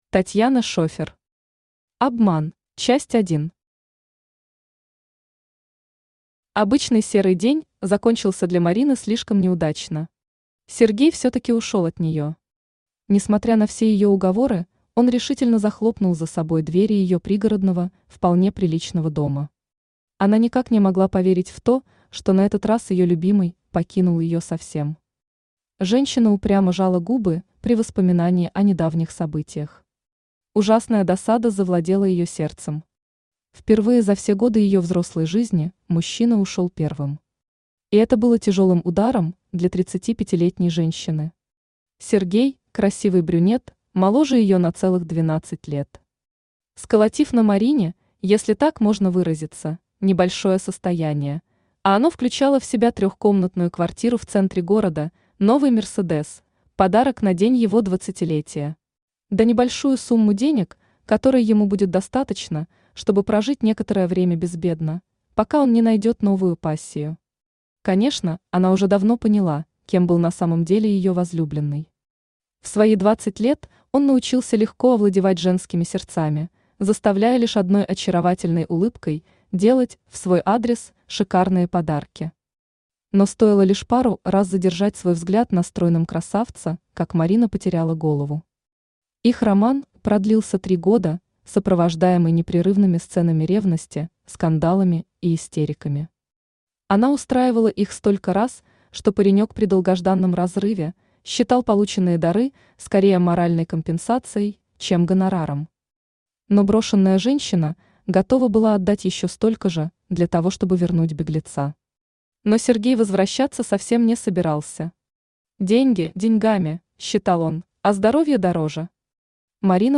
Aудиокнига Обман Автор Татьяна Шефер Читает аудиокнигу Авточтец ЛитРес.